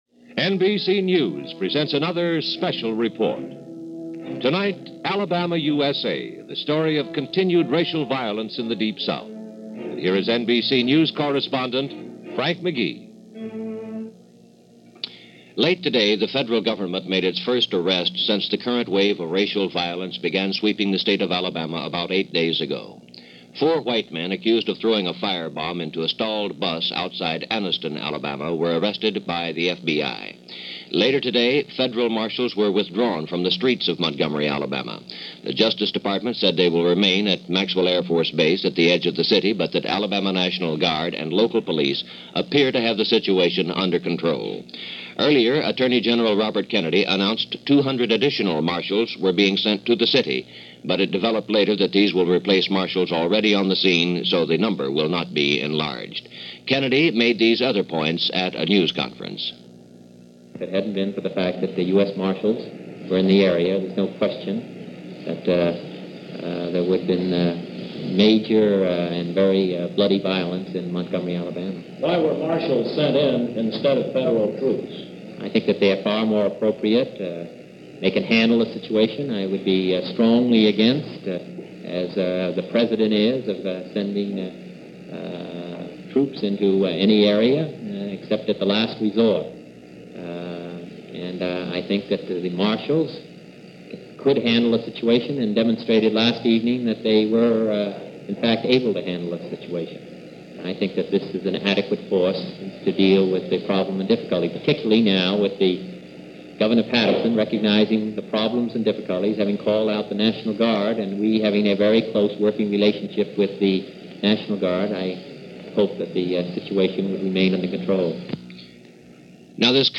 NBC-Special-Report-Alabama-USA-1961.mp3